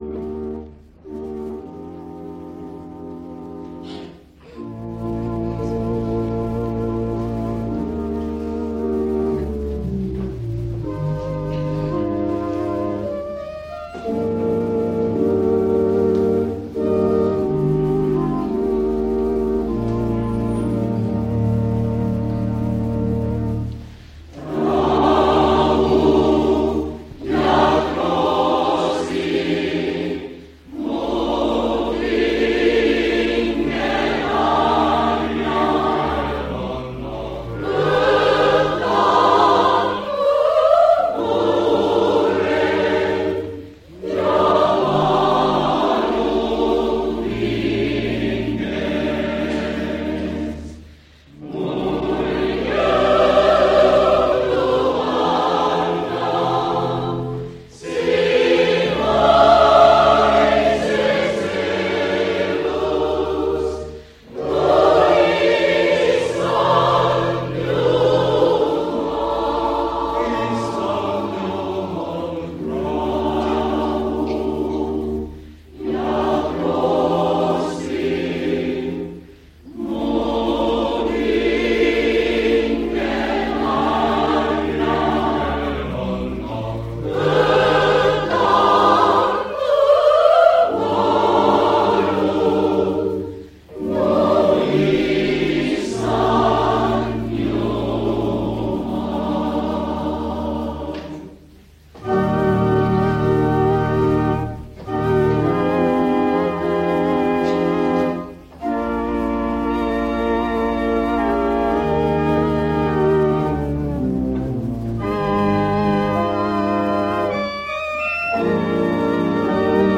Koosolekute helisalvestused
Reedene ja hingamispäevane salvestis vanal lintmaki lindil. Kõnedest on ainult PIIBLITUND koos venekeelse tõlkega.